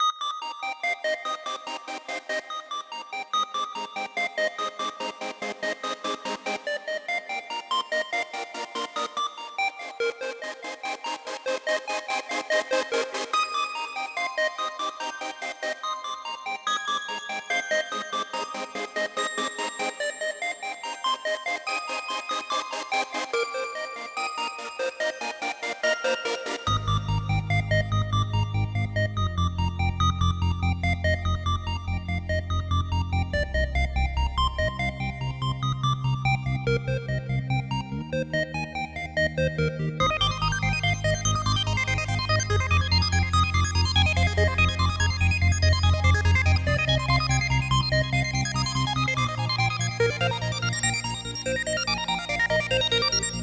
radio rap hit loop_72bpm.wav